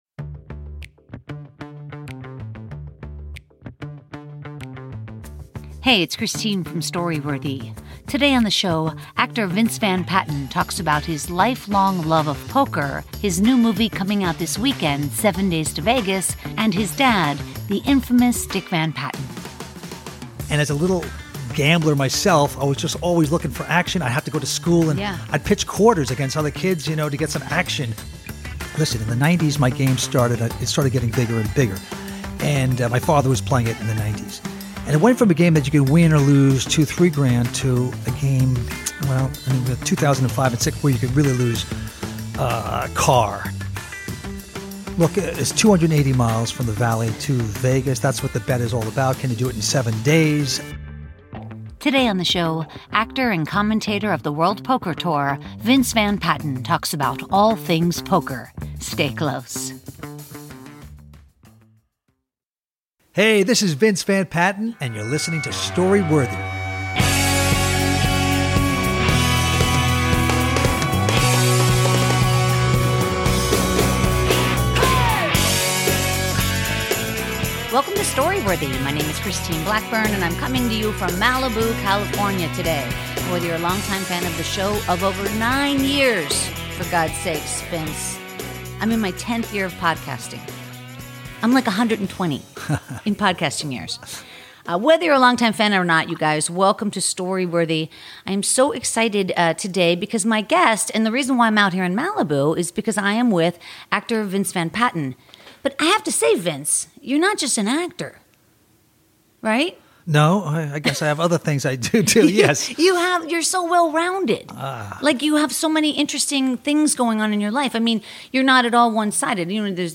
Actor Vince Van Patten (7 Days To Vegas, World Poker Tour) talks about growing up with his dad, actor Dick Van Patten, his love of poker, and his new movie 7 Days To Vegas.